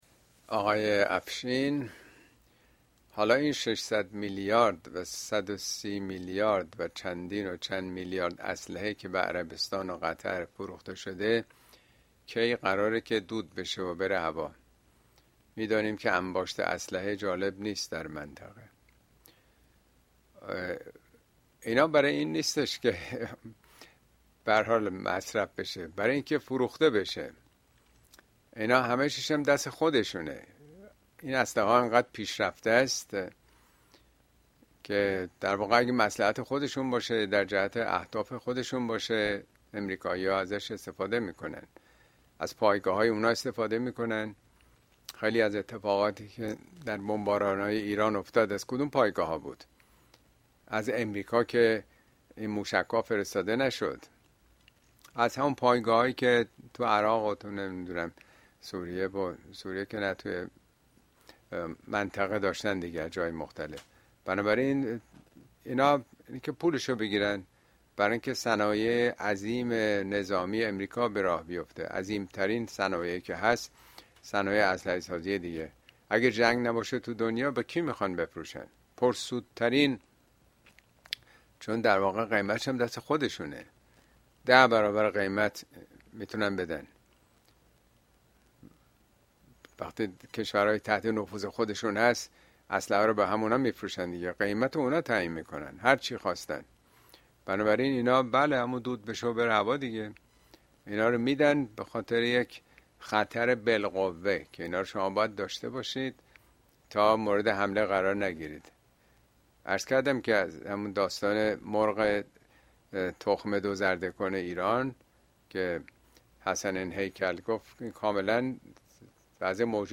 ` موضوعات اجتماعى اسلامى ترامپ و ارتجاع مدرن اين سخنرانى به تاريخ ۲۱ می ماه ۲۰۲۵ در كلاس آنلاين پخش شده است توصيه ميشود براىاستماع سخنرانى از گزينه STREAM استفاده كنيد.